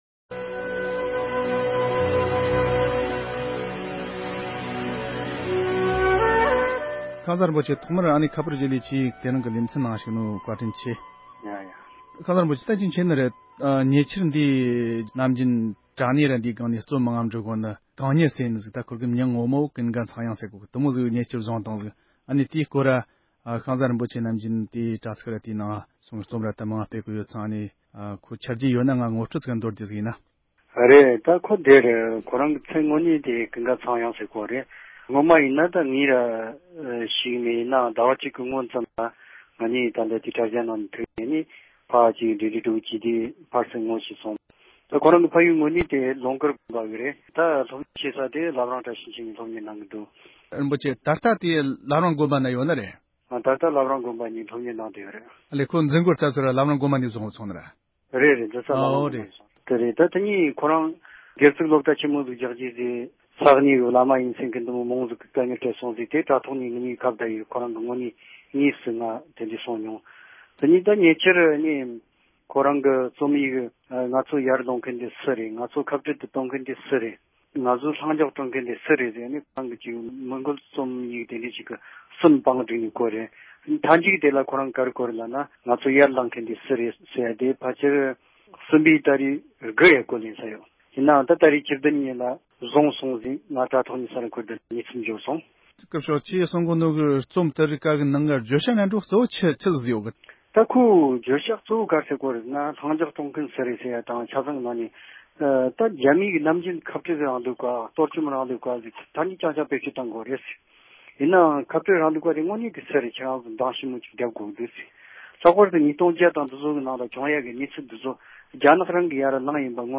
བགྲོ་གླེང་ཞུས་པ་ཞིག་གསན་རོགས་གནང་༎